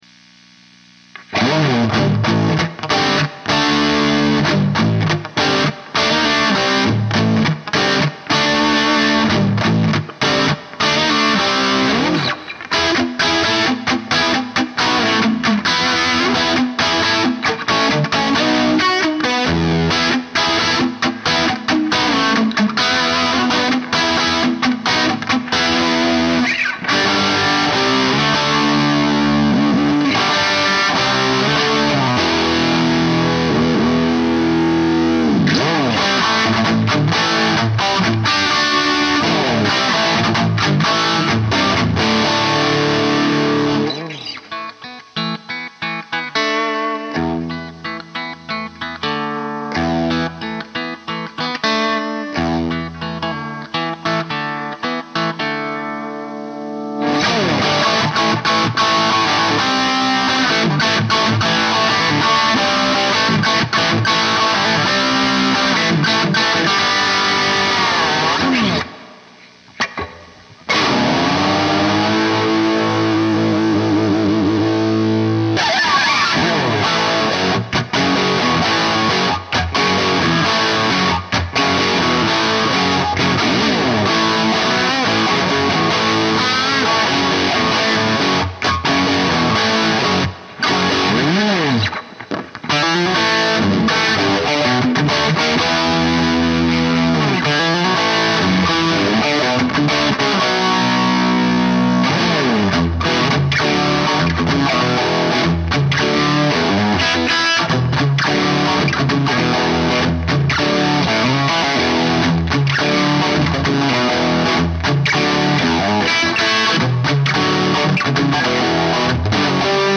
Some people have liked the sound of my amp (